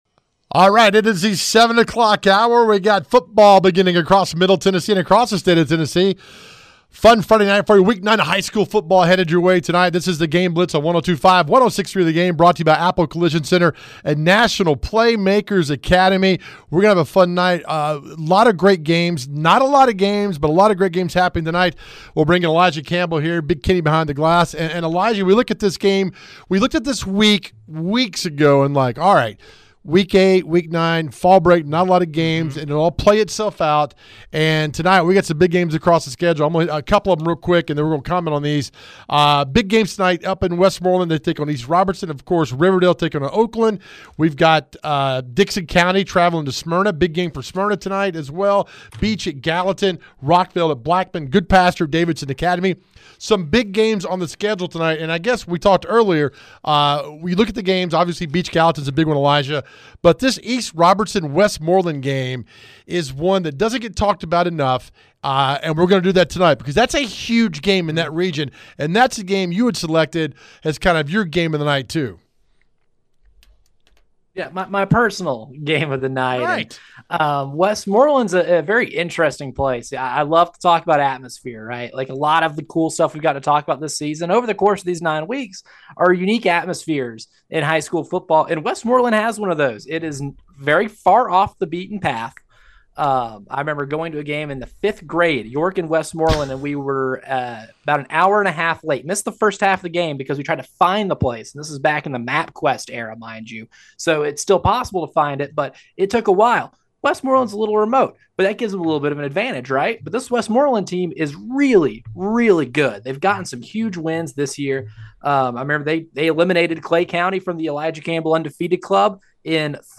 We have game updates and coaches interviews right here.